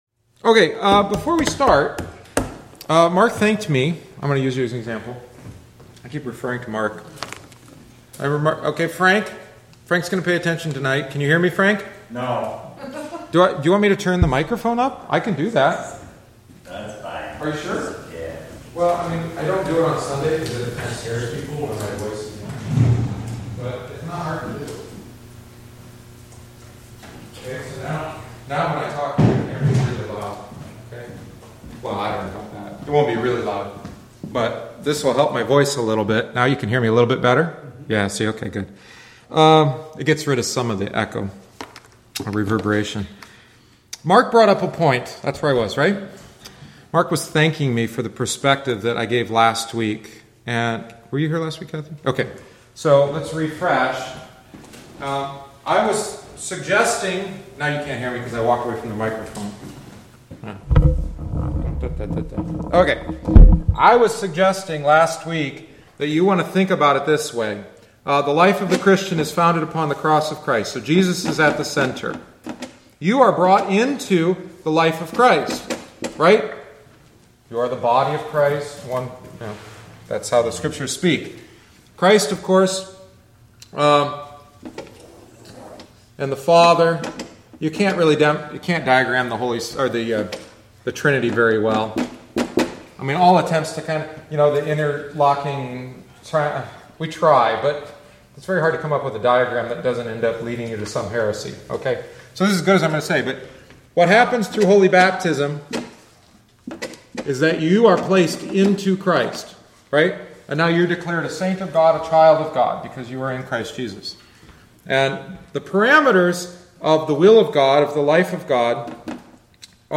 Join us for Adult Catechumenate classes following each Wednesday Divine Service. This is offered for those that would like a refresher course on their catechetical instruction and especially for those desiring to join us and confess the Lutheran faith.